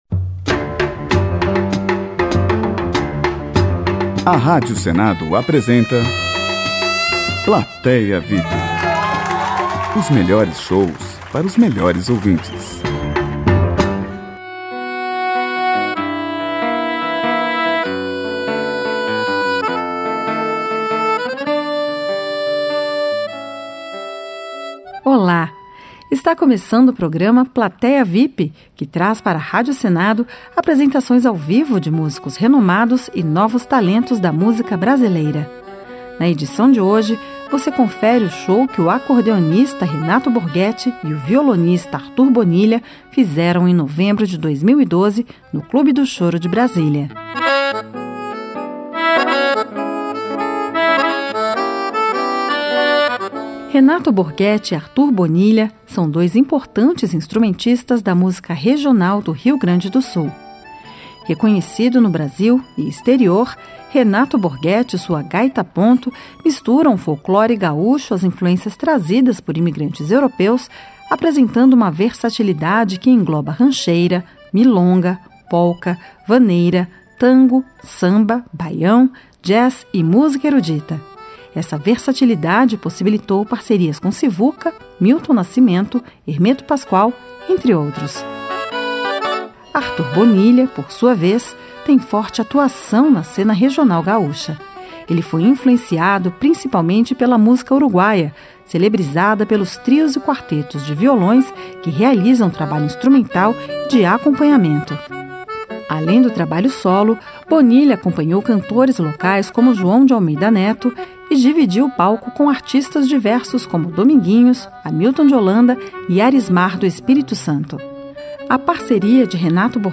acordeonista
violonista